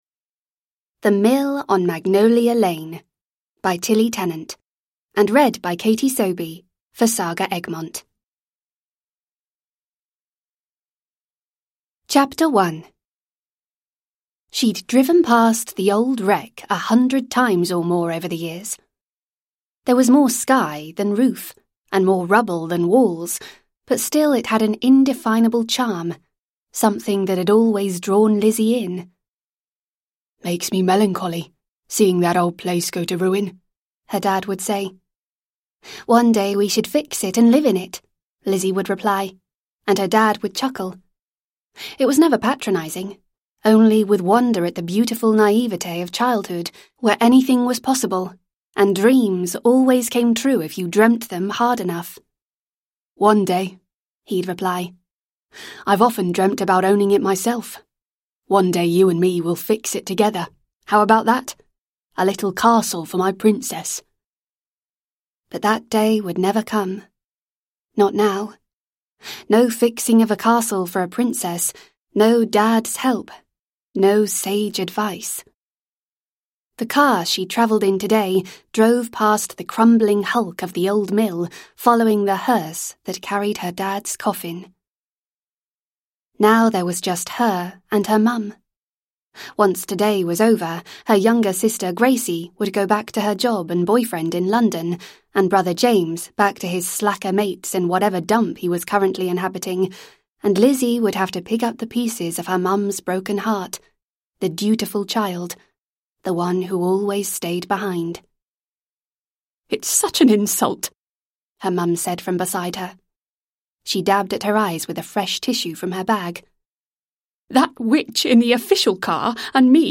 The Mill on Magnolia Lane – Ljudbok